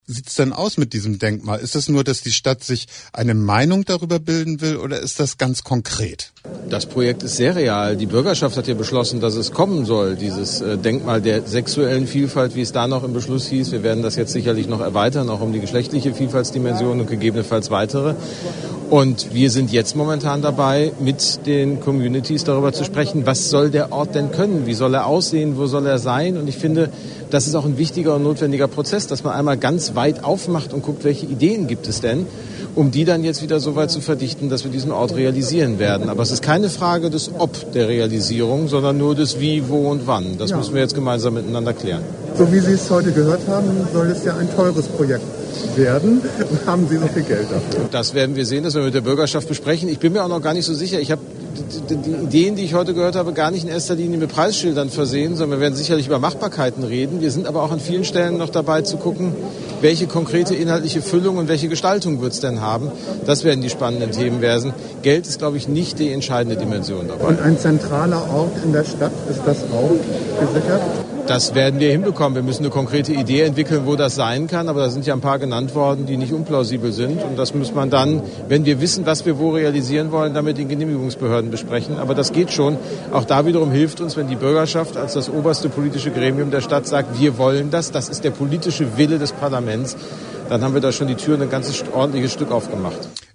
Am Werkstatt-Tag (5.9.2020) zur Gestaltung eines Denkmals für sexuelle und geschlechtliche Vielfalt äußerte sich Kultursenator Dr. Carsten Brosda gegenüber Pink Channel